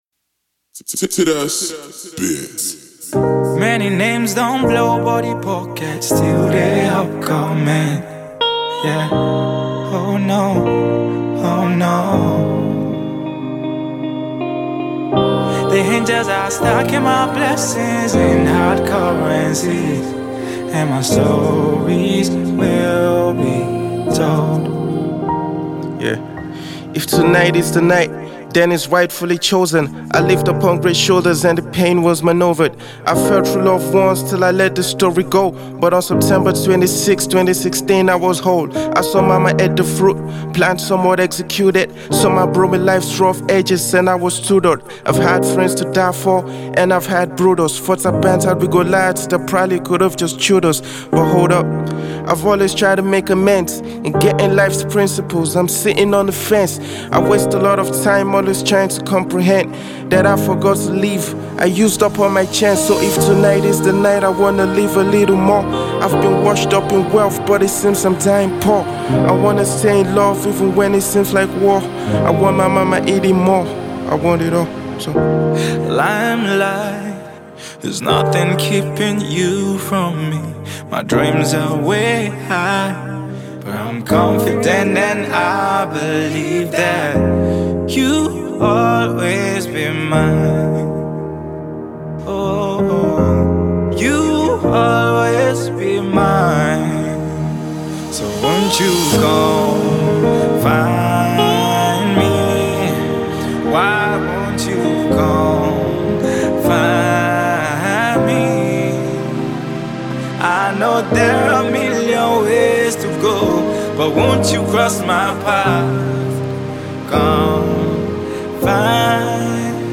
Rapper
skilled delivery and superb punchlines
With a superb hook delivered by vocalist
the song features additional vocals